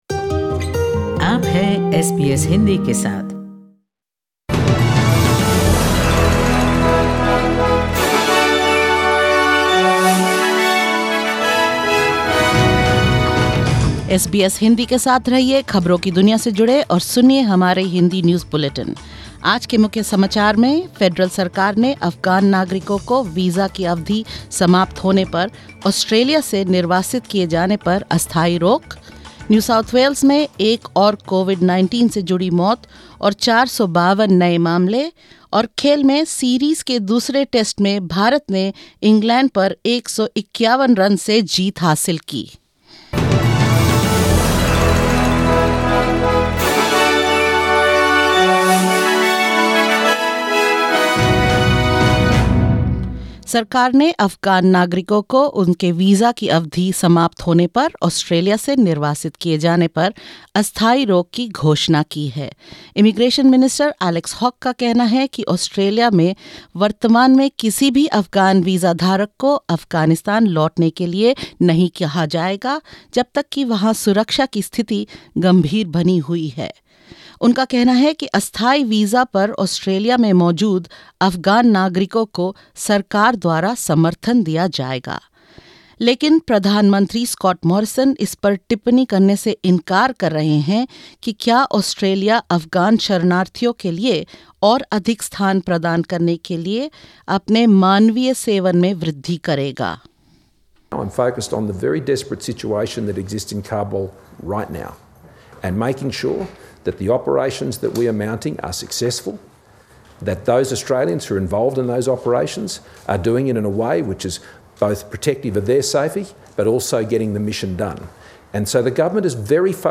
In this latest SBS Hindi News Bulletin: The Australian federal government announces a temporary moratorium on Afghan nationals from being deported when their visas expire; NSW records another 452 local coronavirus infections; India wins the second test against England with 151 runs and more